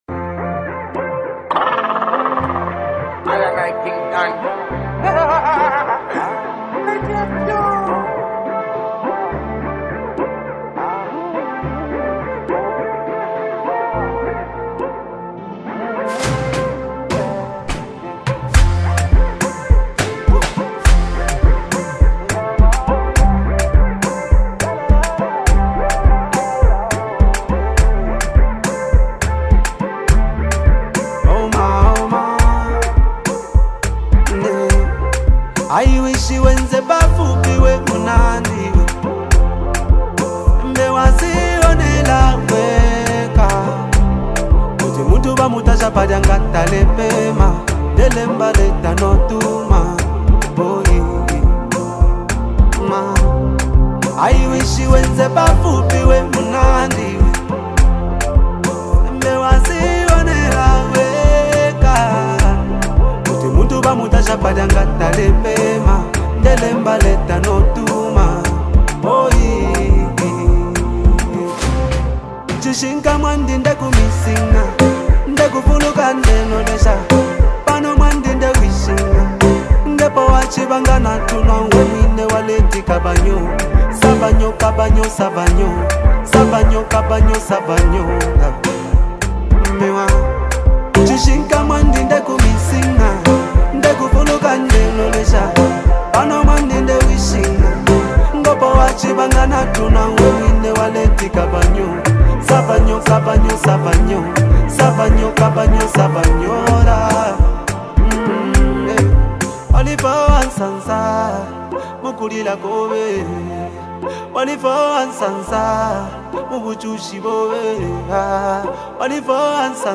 Emotional Tribute Song
Zambian music
heartfelt tribute song